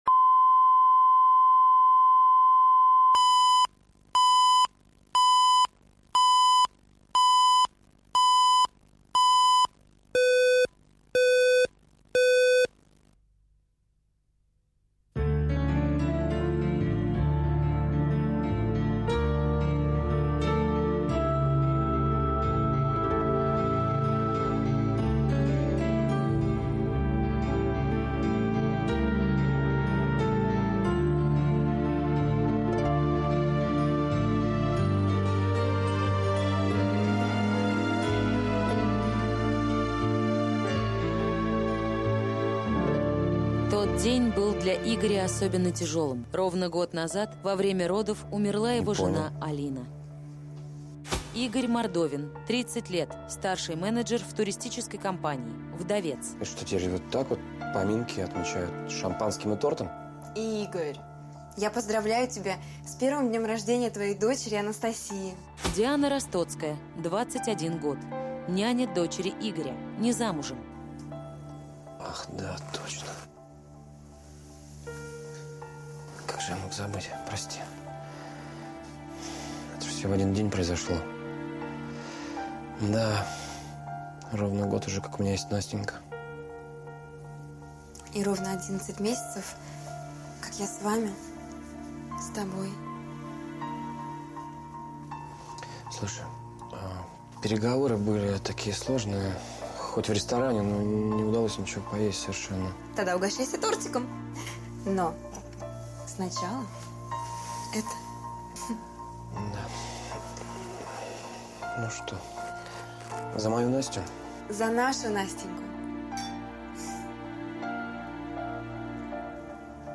Аудиокнига Привет из Варшавы | Библиотека аудиокниг